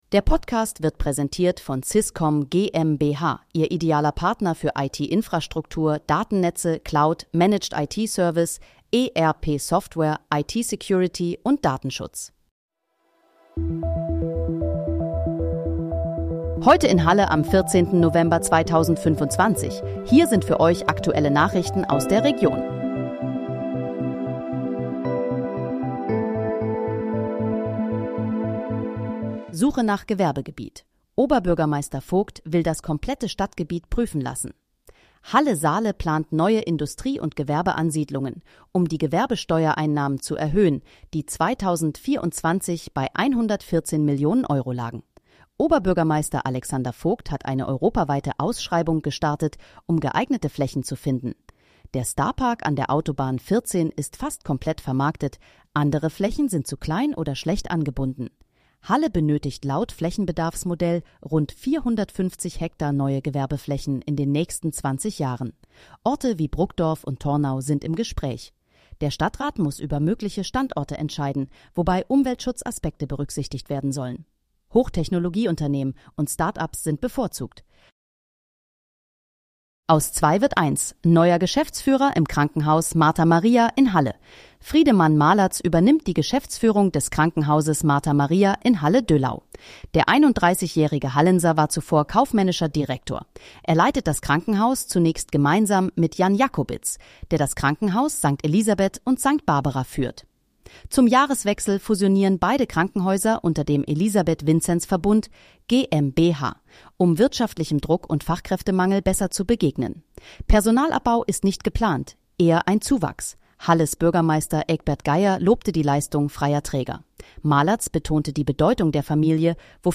Heute in, Halle: Aktuelle Nachrichten vom 14.11.2025, erstellt mit KI-Unterstützung
Nachrichten